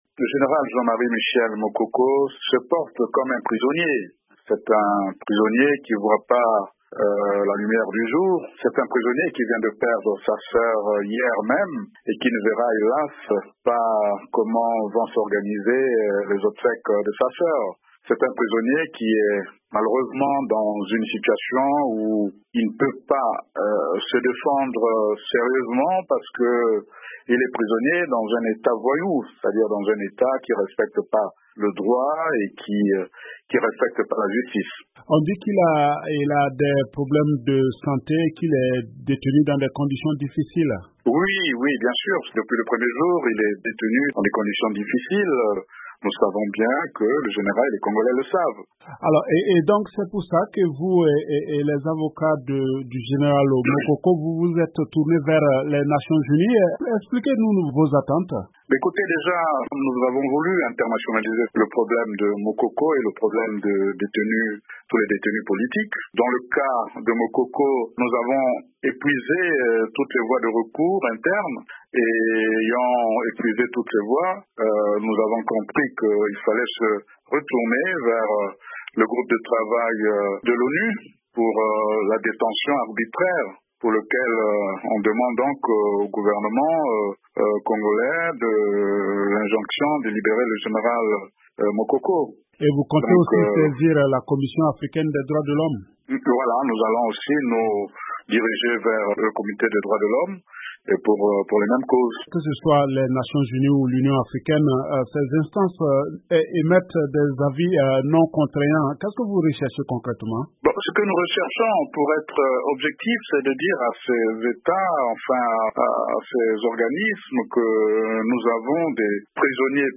joint à Paris